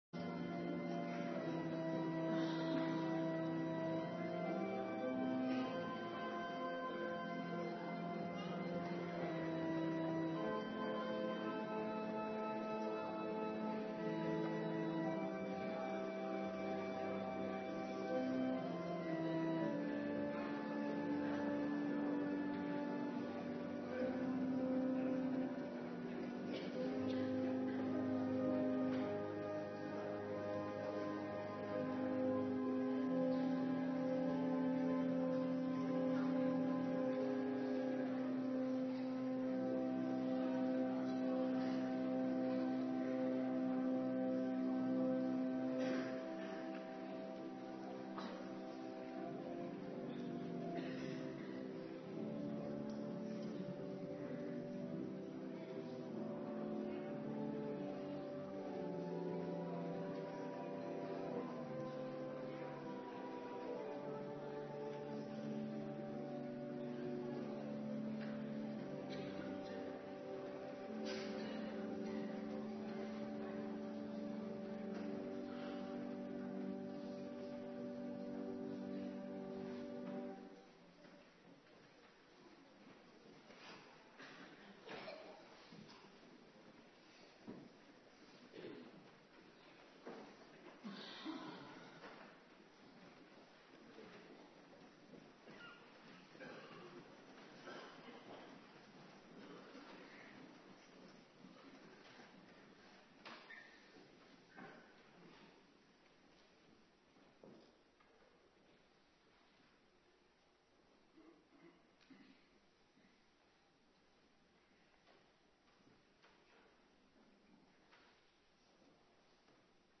Morgendienst
09:30 t/m 11:00 Locatie: Hervormde Gemeente Waarder Agenda